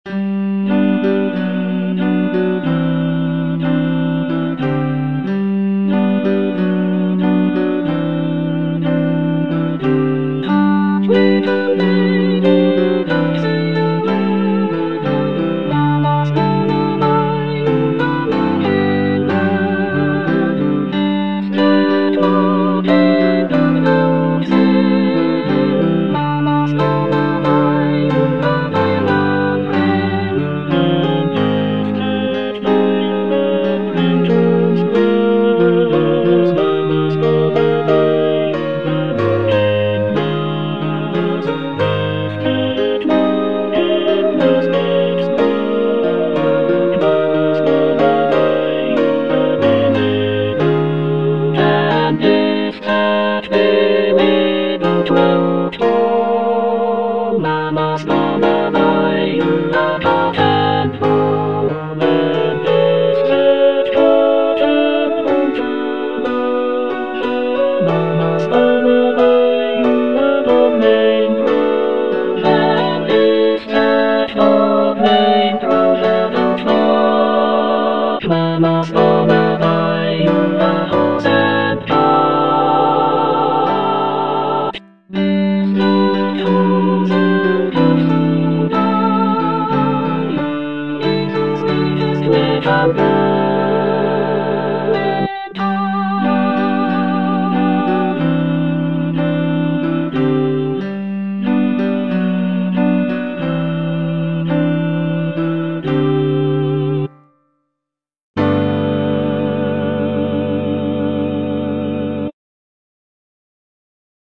(All voices)